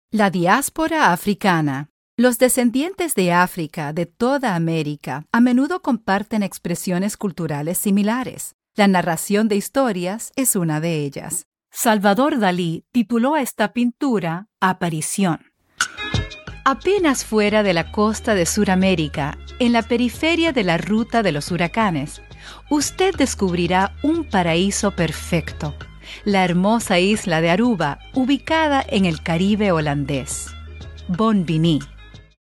Audioguides
Meine Kunden beschreiben meine Stimme am besten als freundlich, spritzig, ausdrucksstark, angenehm, warm und enthusiastisch